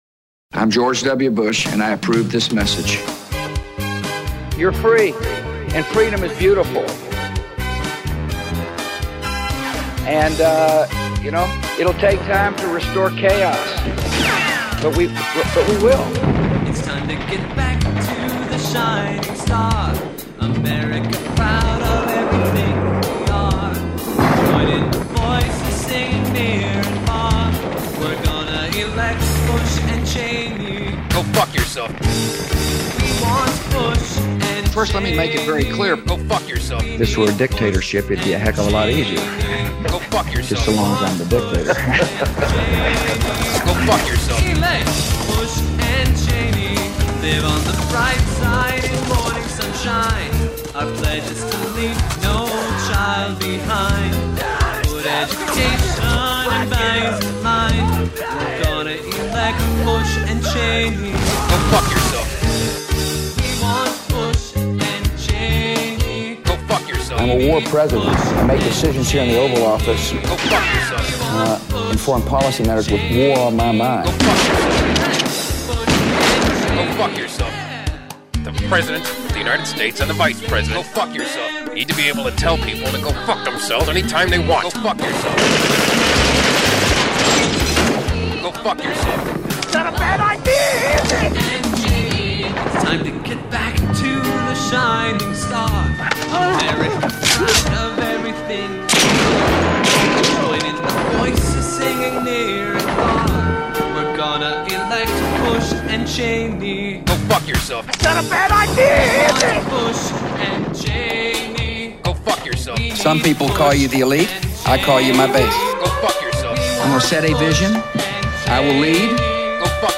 Great audio montage featuring a wack pro-Bush track and a Cheney impersonator giving the Veep's trademark soundbite.